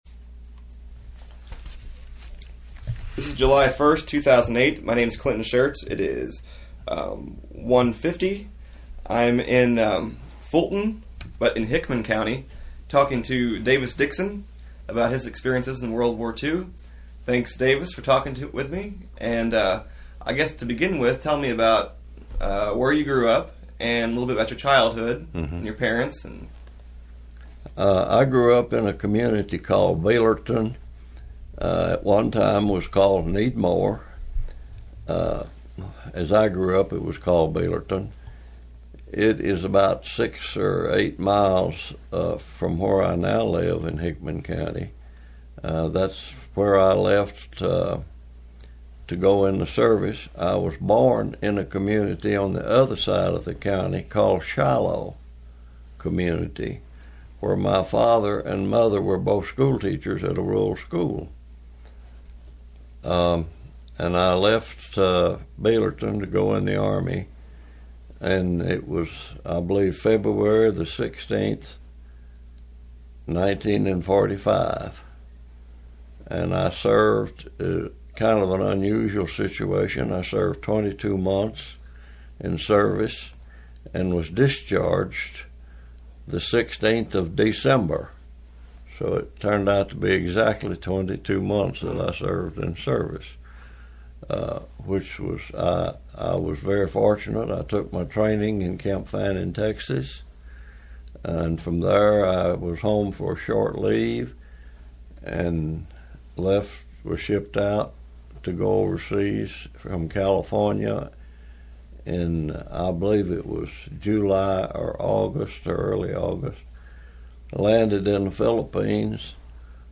World War II Veterans of Kentucky Oral History Project